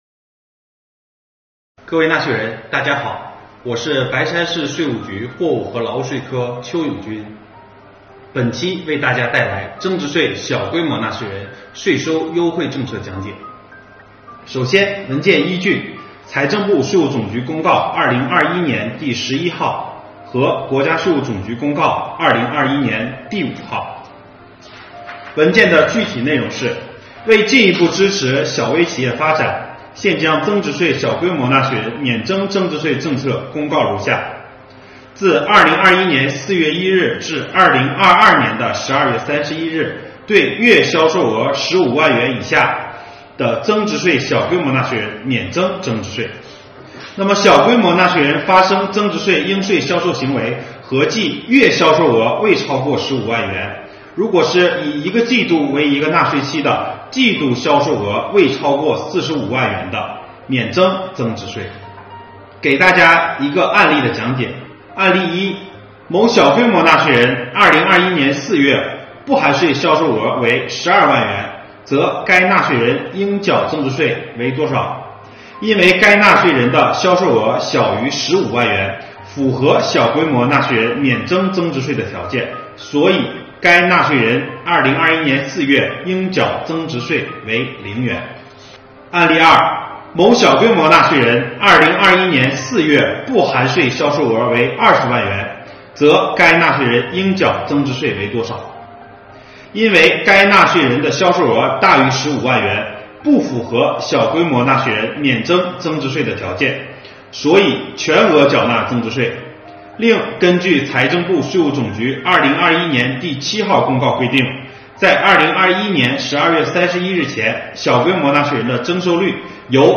白山市税务局开展第三十个税收宣传月线上政策宣讲会，邀请全市纳税人线上学习纳税知识。